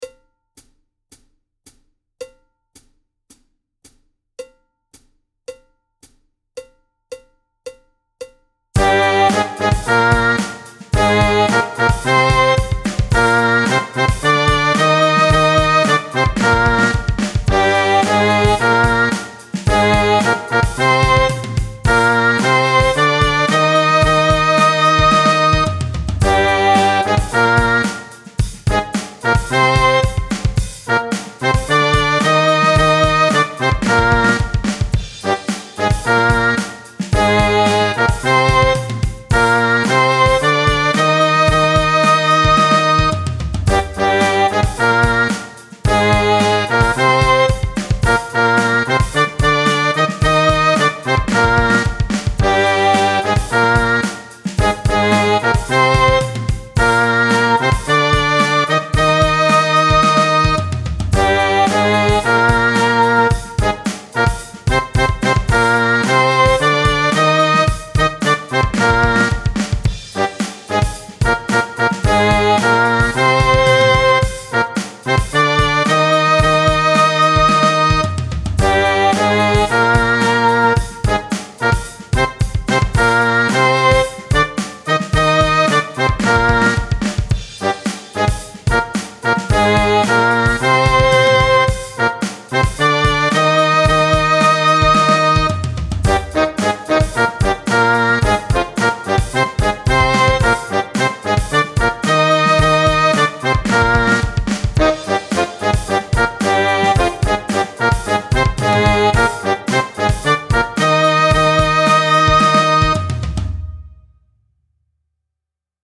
– die Stimmung ist 440Hertz
– der Einzähler des Playbacks beginnt 4 Takte vor dem Song
– das Playback ist aktuell noch eine Midi-Version
Zweistimmige Übung - Tempo 110
Nr. 14 bis 19 | nur 1. Stimme